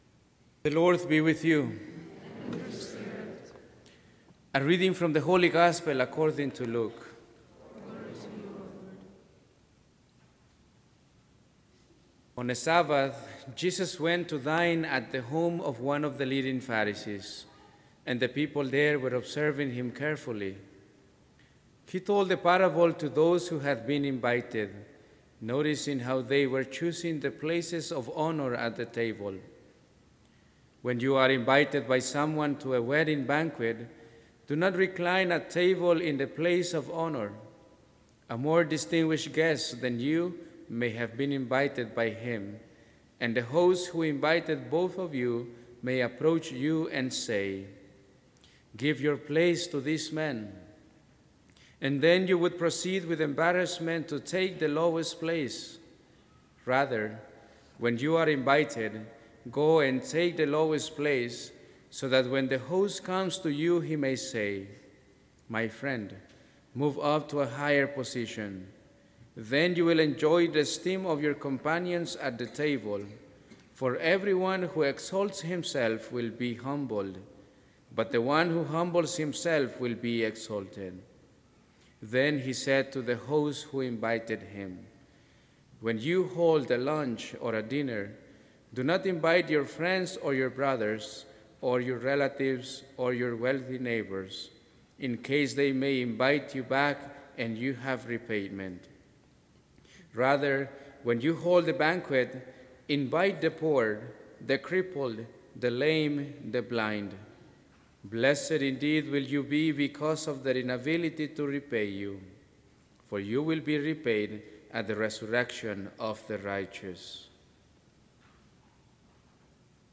Homily for Sunday September 01, 2019